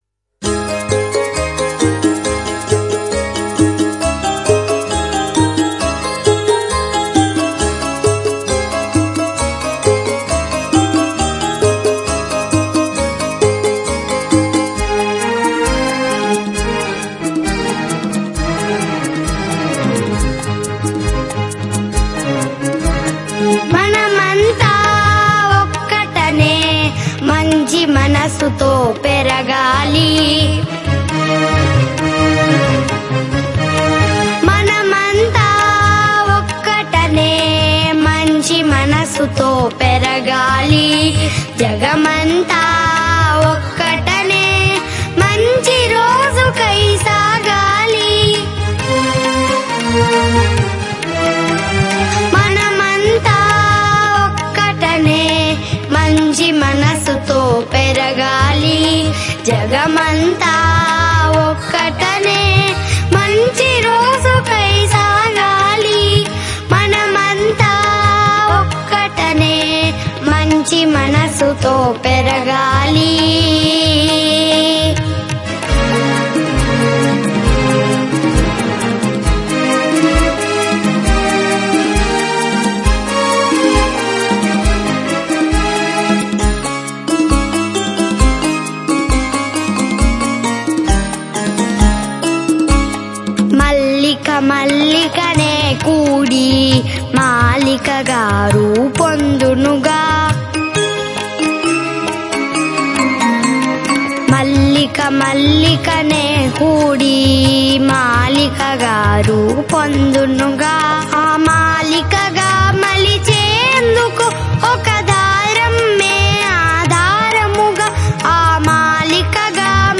Ragam - Sarasangi